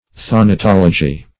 Thanatology \Than`a*tol"o*gy\ (th[a^]n`[.a]*t[o^]l"[-o]*j[y^]),
thanatology.mp3